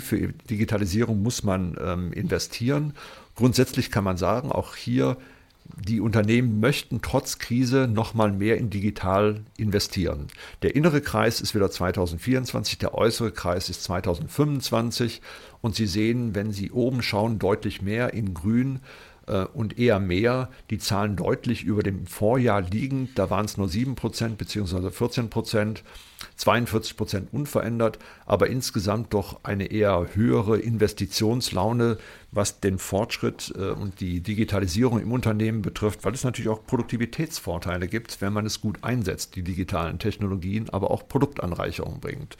Mitschnitte der Pressekonferenz
pressekonferenz-digitalisierung-der-wirtschaft-2025-digitale-investitionen.mp3